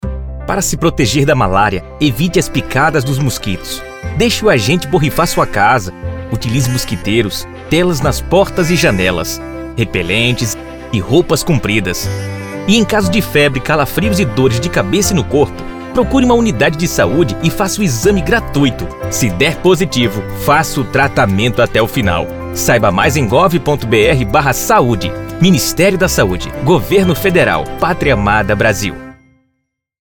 Spot - Malária MP3 - 30seg